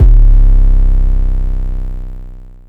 X 808.wav